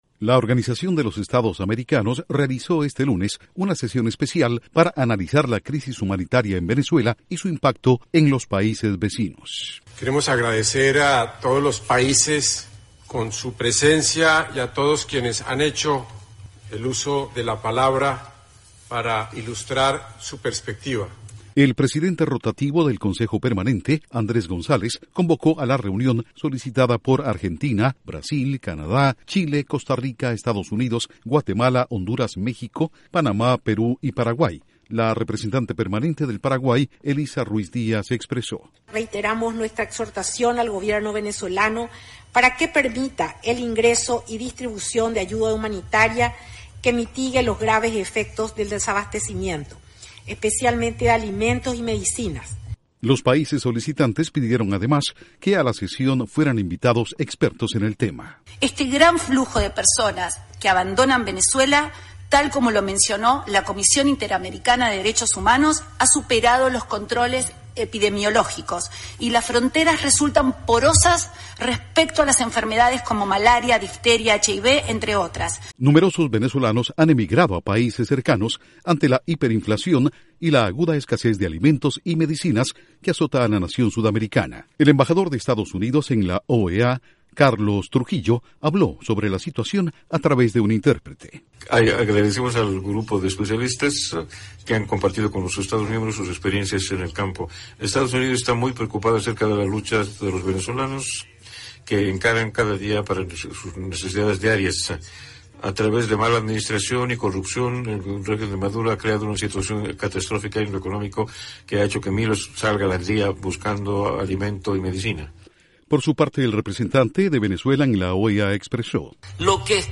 OEA analiza en sesión especial la crisis humanitaria en Venezuela y el efecto en naciones vecinas. Informa desde la Voz de América en Washington